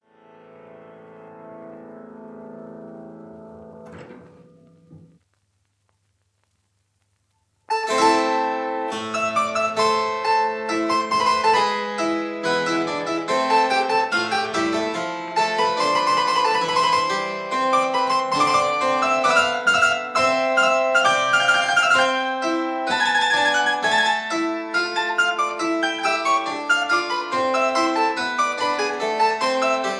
harpsichord
(I: 16,8,4 leather; II: 8 quill, 8 lute)